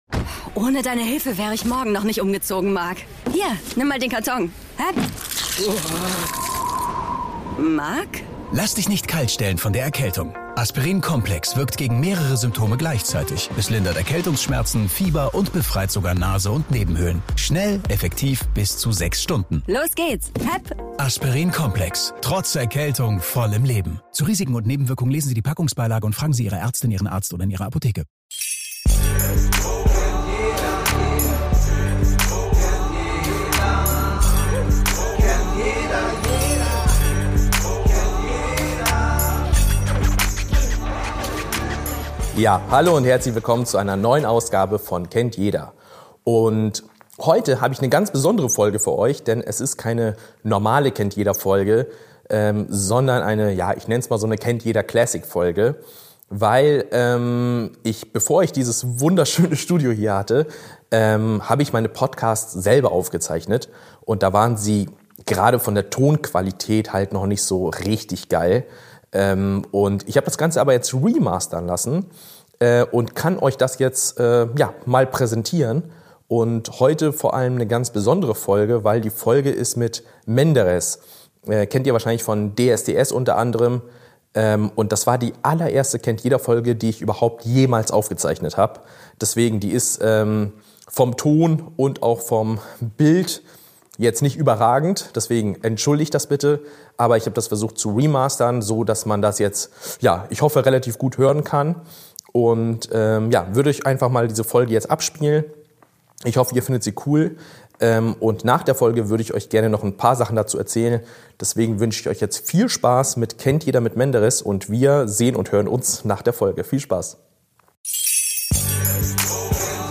Da die Tonqualität damals nicht optimal war, hat das Kennt-jeder-Team die Folge nun neu gemastert und macht dieses besondere Gespräch erneut für euch verfügbar.
Es geht um Einsamkeit, Selbstzweifel, aber auch um Hoffnung und Träume – zum Beispiel seinen großen Wunsch, eines Tages bei „Let’s Dance“ mitzutanzen. Eine ruhige, ehrliche und tiefgründige Folge, die zeigt: Hinter dem bekannten Gesicht steckt ein Mensch mit einer bewegenden Geschichte.